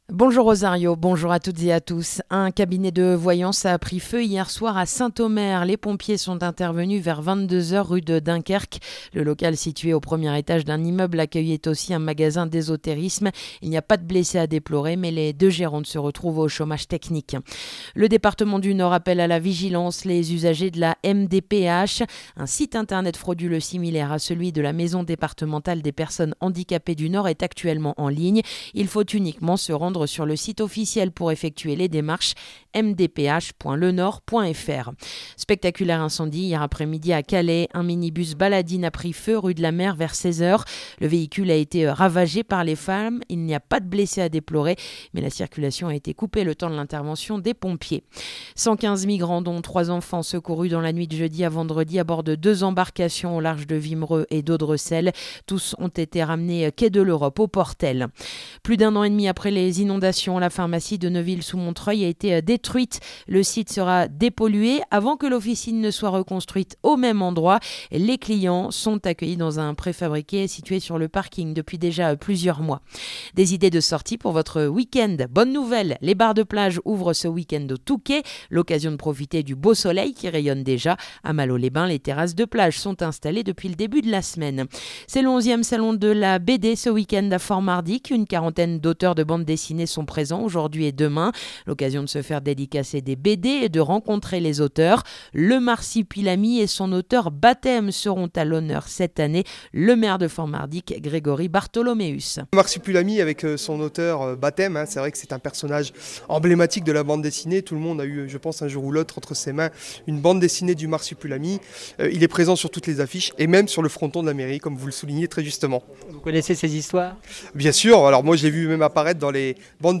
Le journal du samedi 5 avril